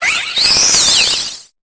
Cri de Rubombelle dans Pokémon Épée et Bouclier.